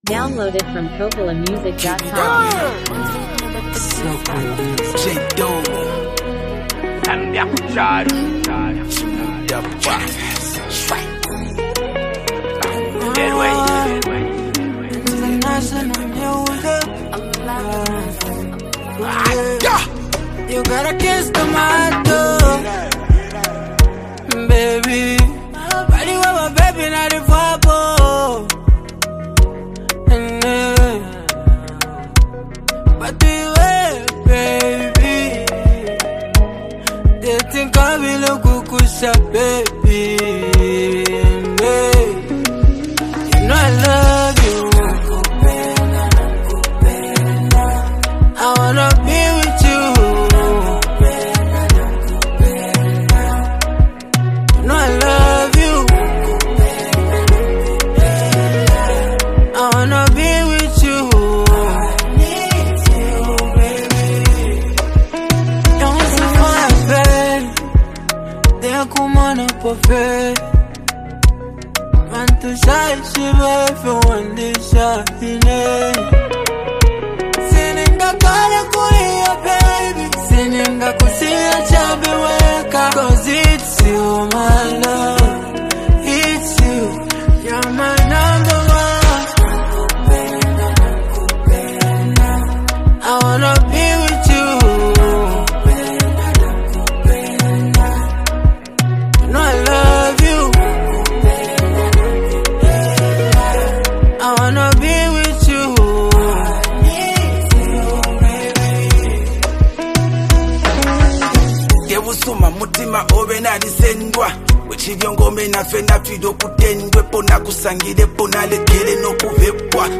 signature emotional touch
brings energy and street flavor